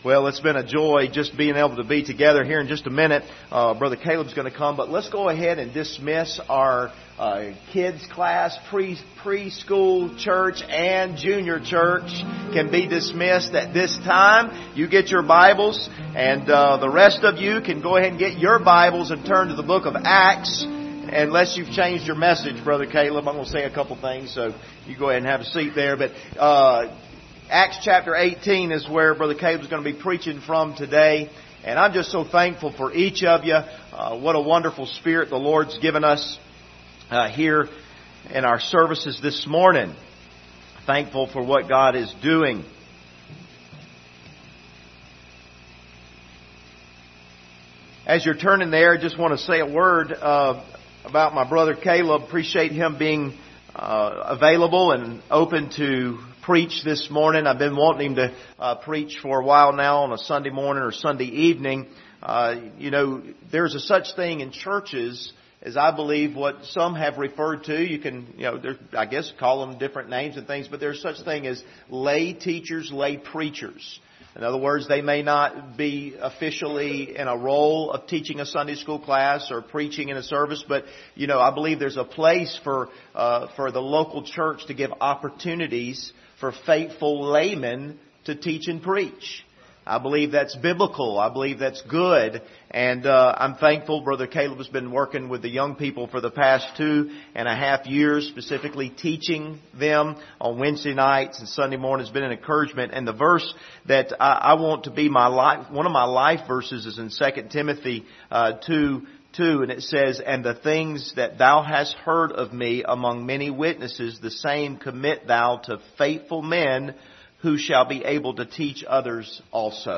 Passage: Acts 18:24-28 Service Type: Sunday Morning Topics